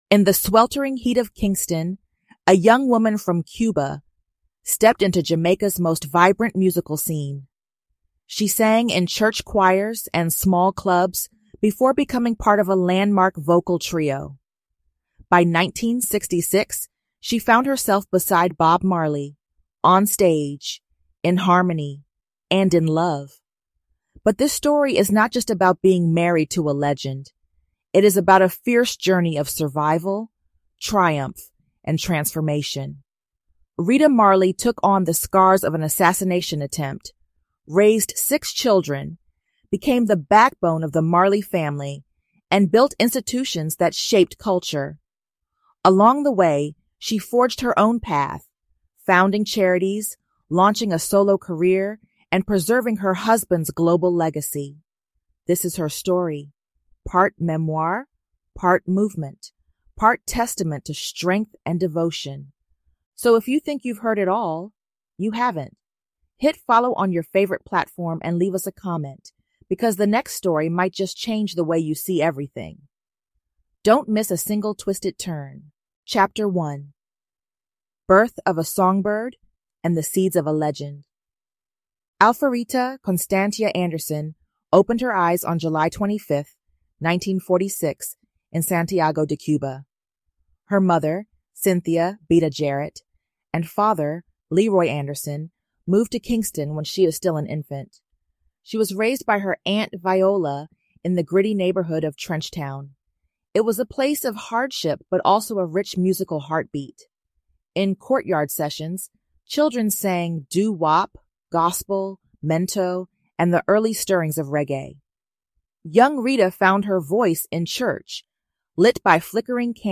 Rita Marley was the voice behind the movement, the mother of a musical dynasty, and the quiet power who protected a global legacy. In this 7-chapter cinematic audio documentary, we follow her journey from Trenchtown choirgirl to Queen Mother of Ghana.